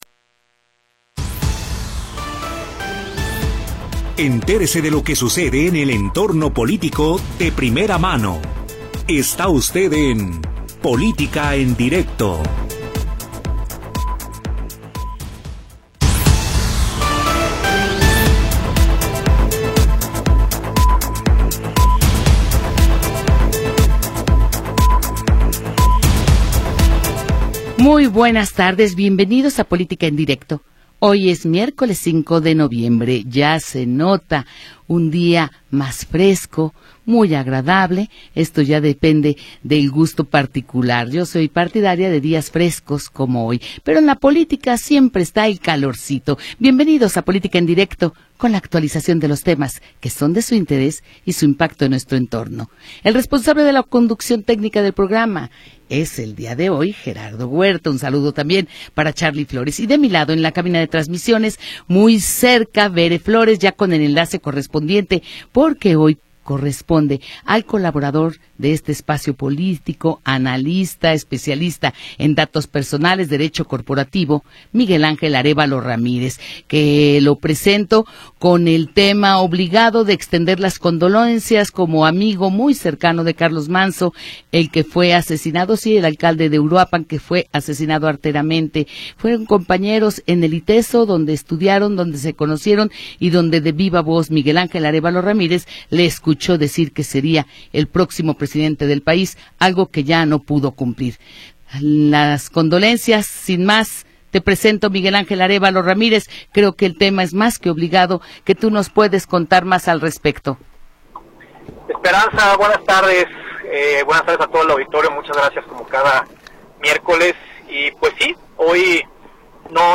Programa transmitido el 5 de Noviembre de 2025.